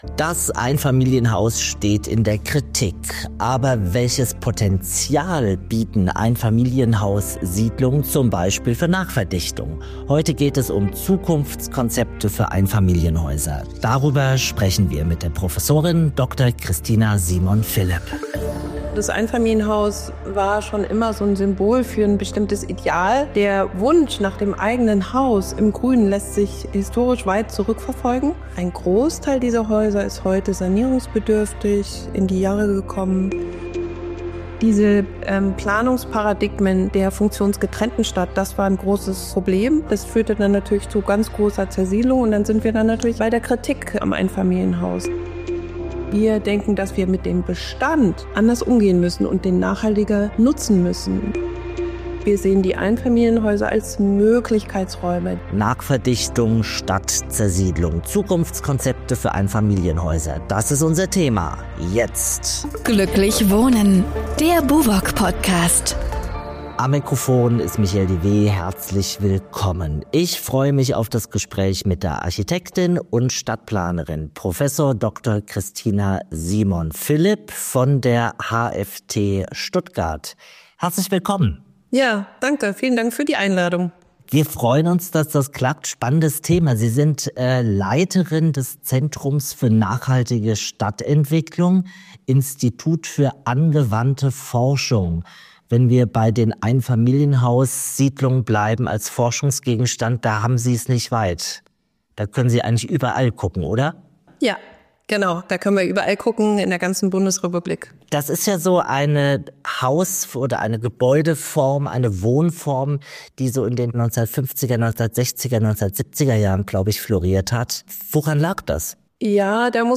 Wie kann man Einfamilienhaussiedlungen beleben, verdichten und nachhaltig weiterentwickeln? Ein Interview